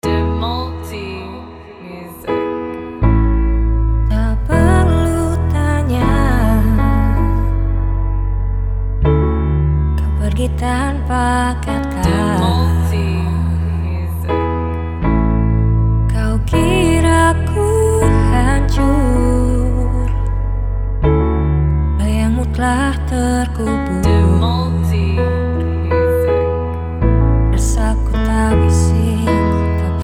Namun alunannya tidak jatuh pada muram, melainkan reflektif.